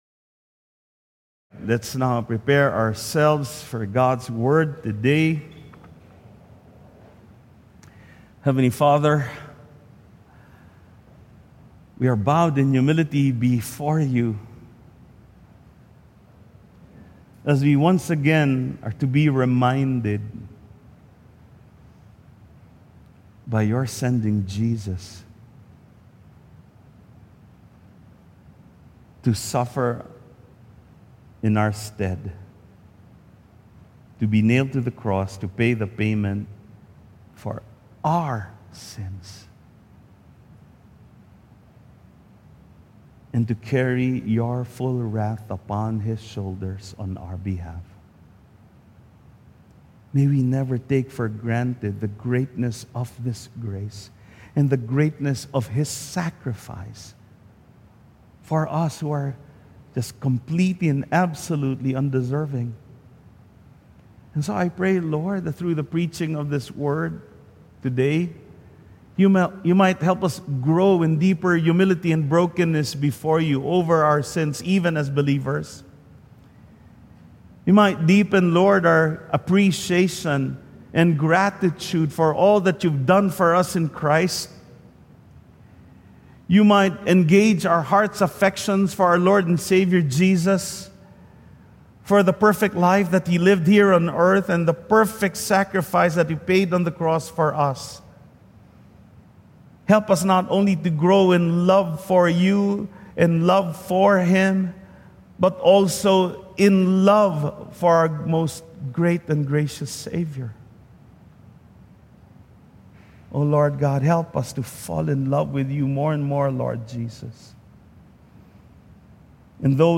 WATCH AND BE BLESSED 2025 Holy Week Message 1 Videos April 13, 2025 | 9 A.M Service 2025 Holy Week Message: Finding Comfort in Christ’s Suffering on the Cross | Isaiah 53:4-6 Information Information Download the Sermon Slides here.